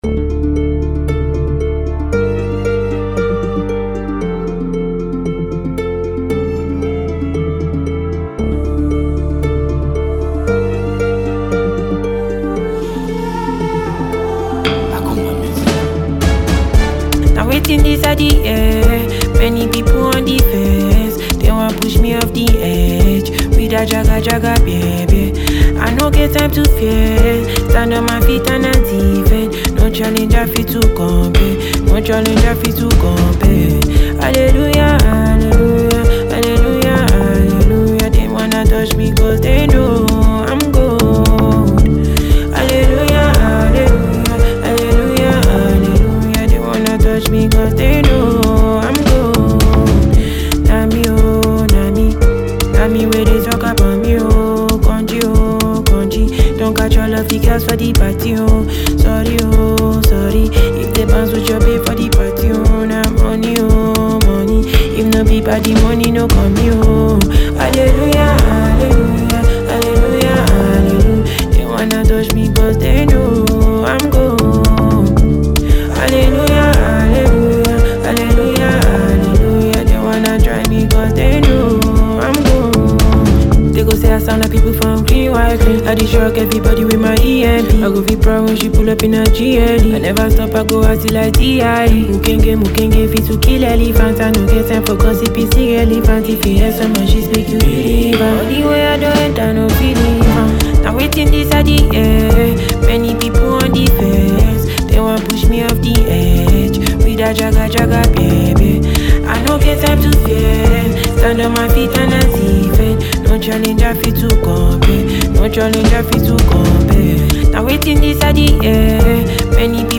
an afrobeat singer